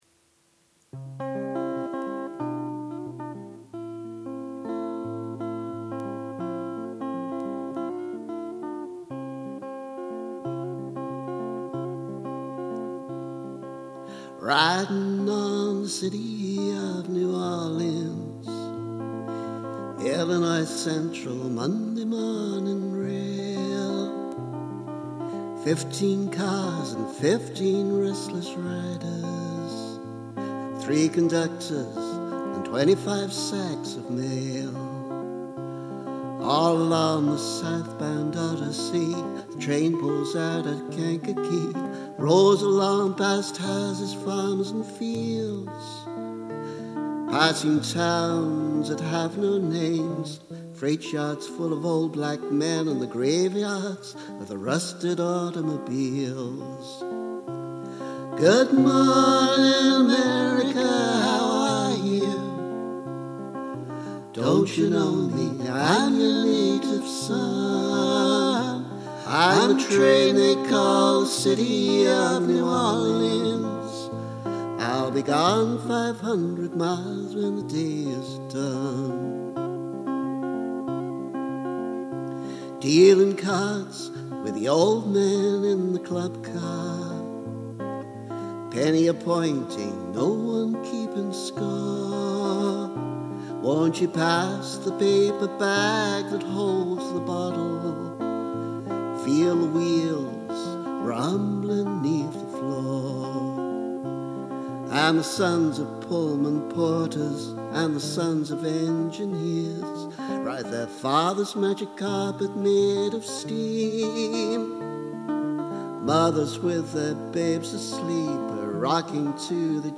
City of New Orleans, with Gibson amplified.